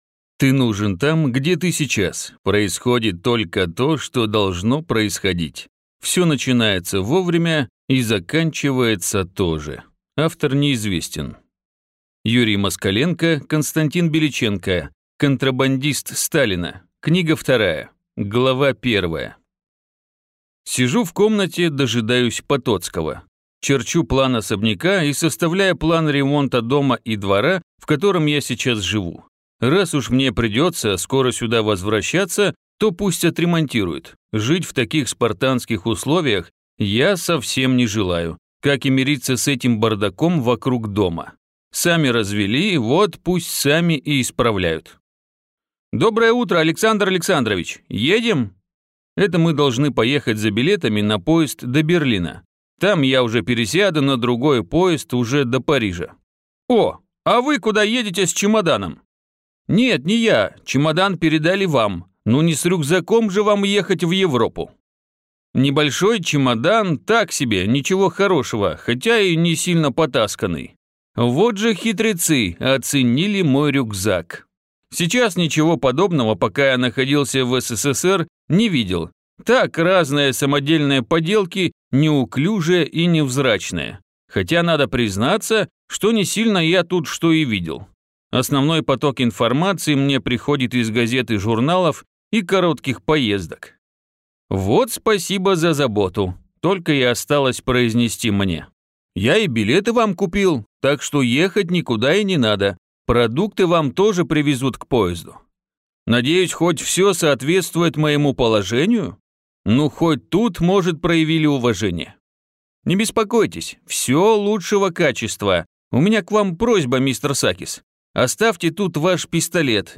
Аудиокнига Контрабандист Сталина Книга 2 | Библиотека аудиокниг